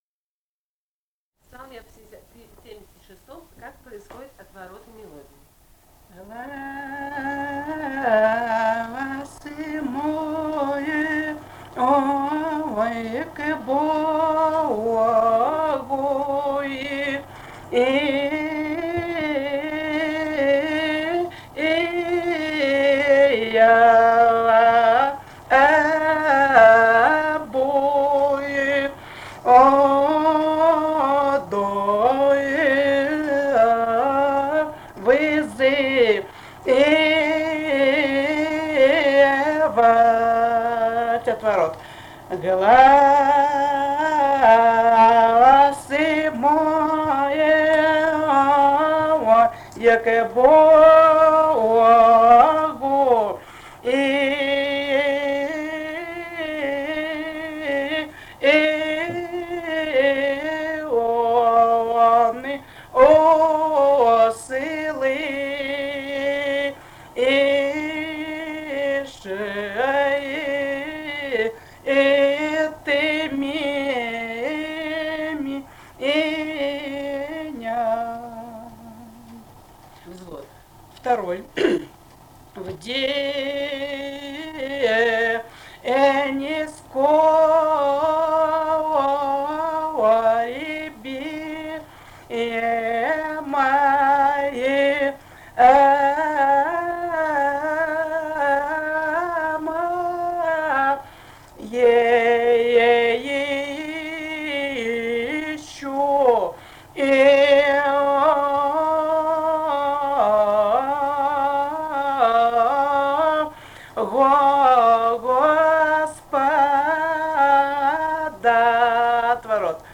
Этномузыкологические исследования и полевые материалы
Псалом 76 (показывает отвороты в мелодии).
Грузия, г. Тбилиси, 1971 г. И1311-13